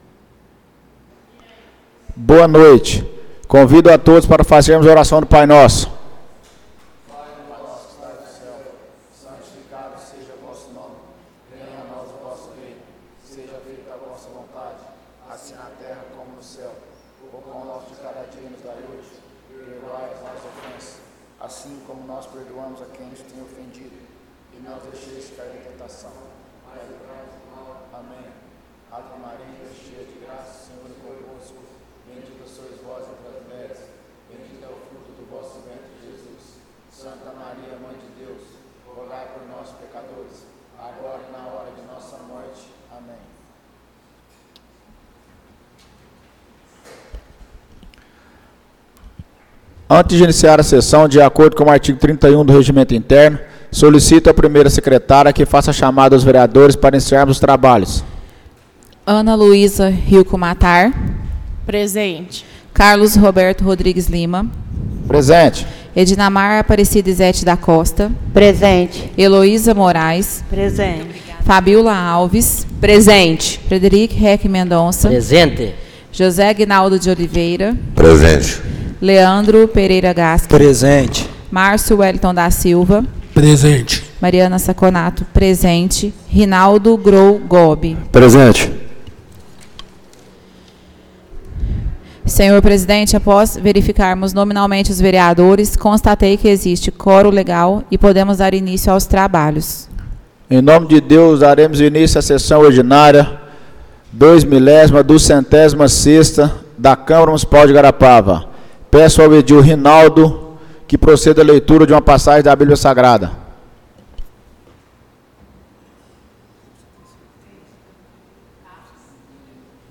Áudio da Sessão Ordinária - 07/04/2025